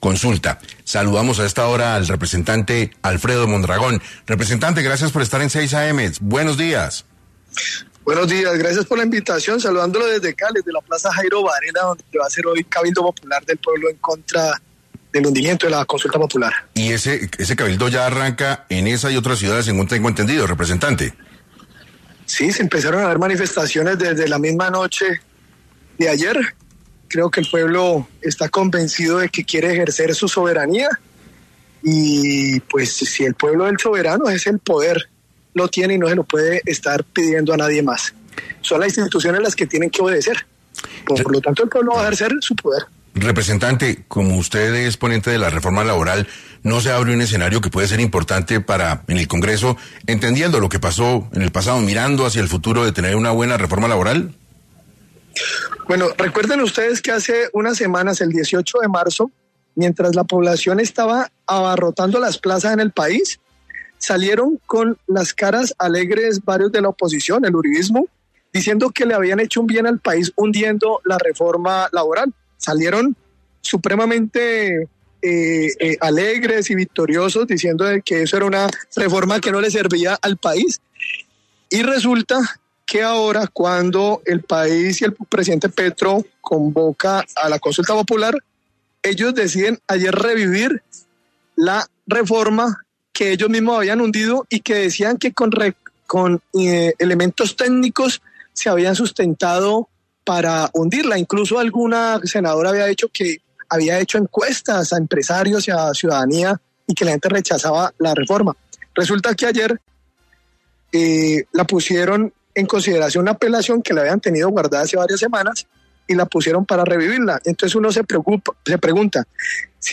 El representante a la Cámara Alfredo Mondragón, del Pacto Histórico, y el senador Alirio Barrera, del Centro Democrático, hicieron un cara a cara tras el hundimiento de la consulta popular en 6AM de Caracol Radio.